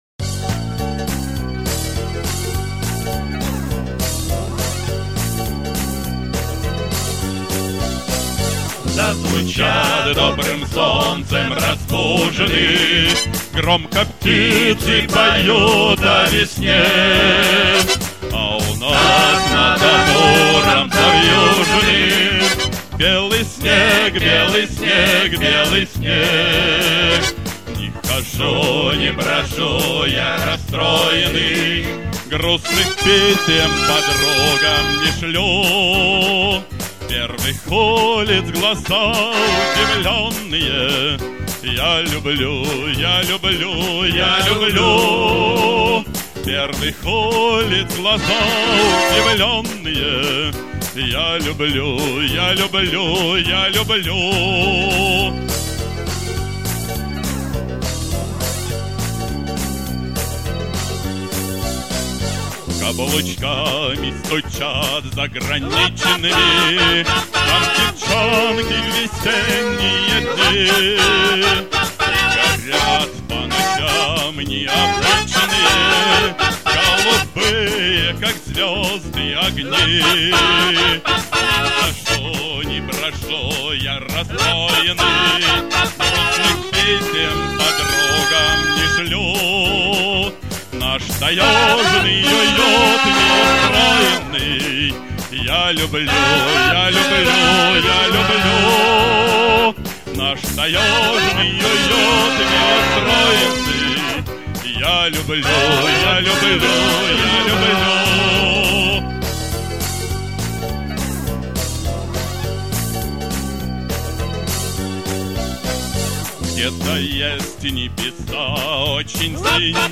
Песня на стихи Выборова К. Р. «Песня о юном городе» на музыку Ю.Леонова